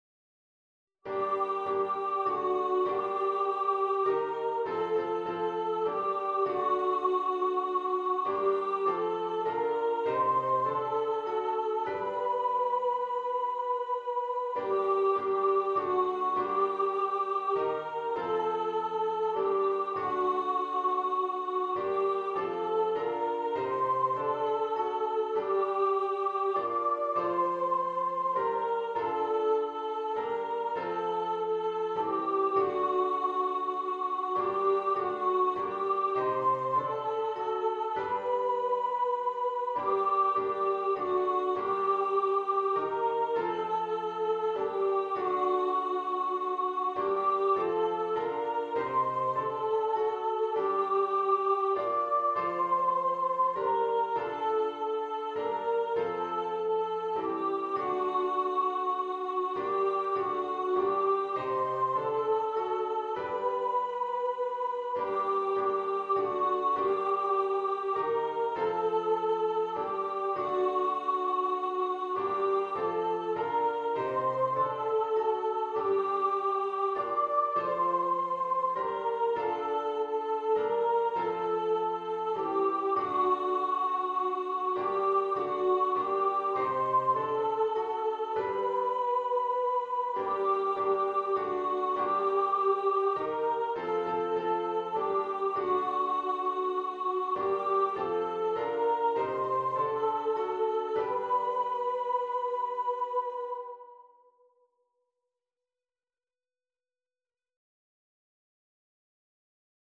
Coventry-Carol-Soprano.mp3